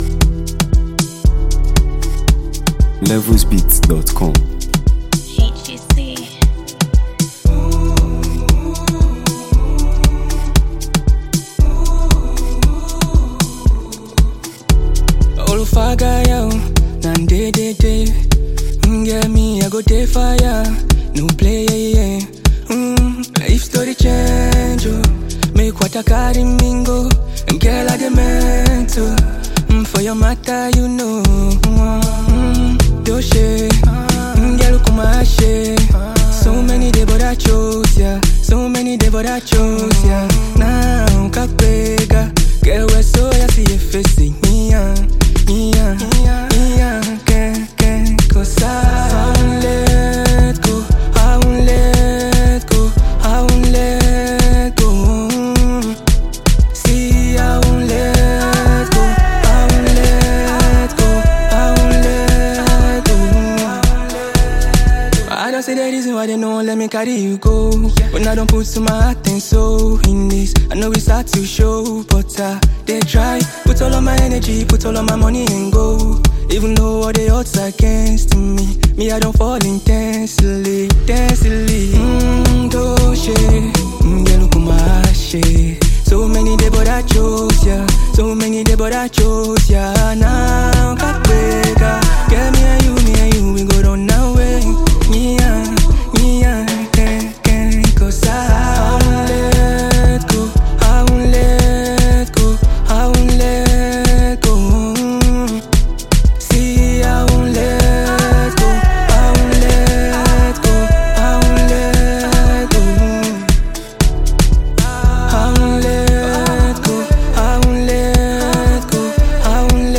a soulful and emotive track